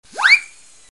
Fischio corto sirena
Fischio corto con slittamento frequenza. Effetto da gag.